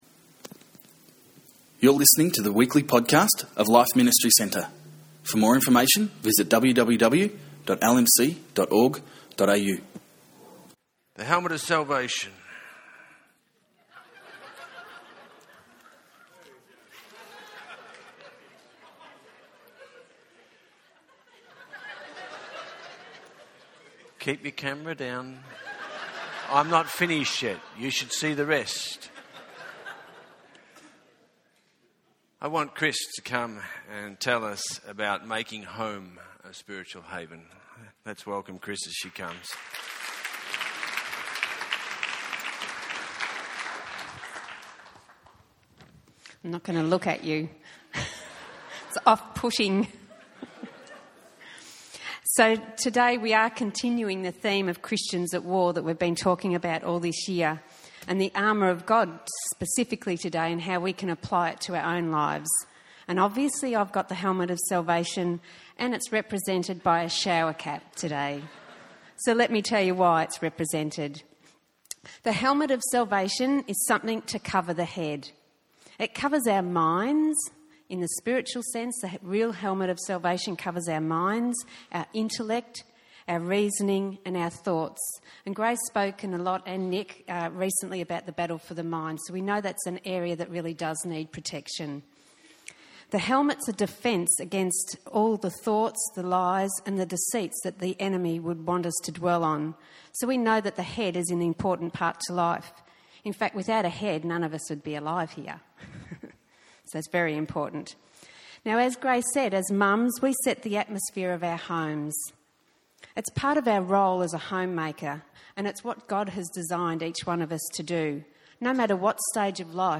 In this special Mother's Day service
several of the ladies from LMC, speak from a Mother's point on the importance of the Armour of God and how it can help to bring peace to our homes.